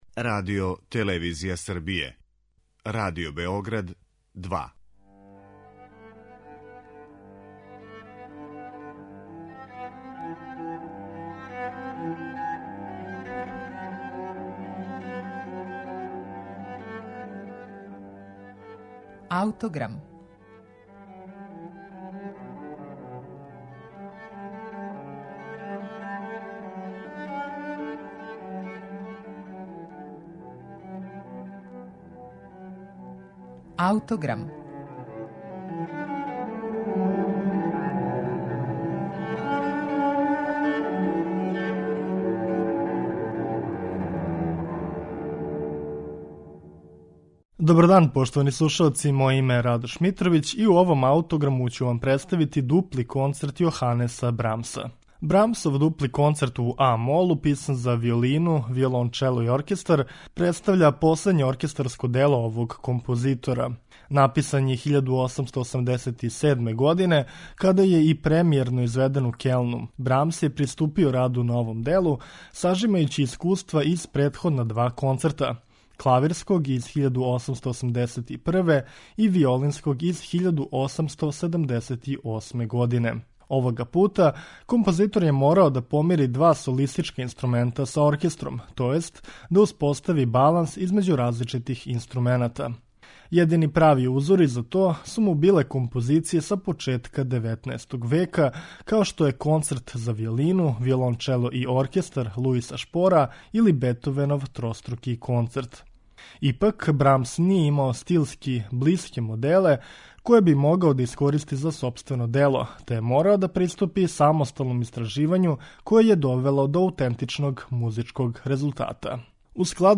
у а- молу, писан за виолину, виолончело и оркестар
створивши јединствено дело позног романтизма.